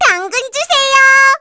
Luna-Vox_Victory_03_kr.wav